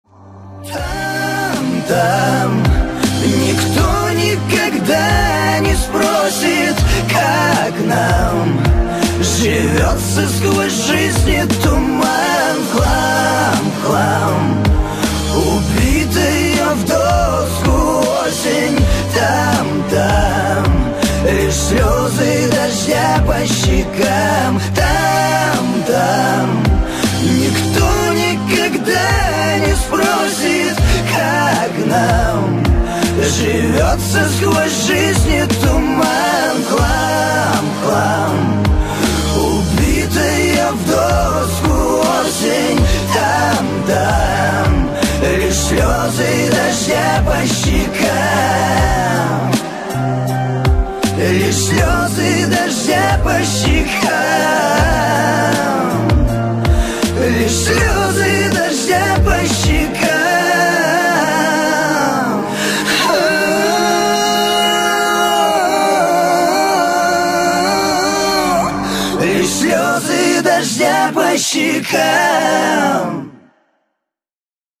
• Качество: 320, Stereo
мужской вокал
душевные
грустные
спокойные
русский шансон
печальные
лиричные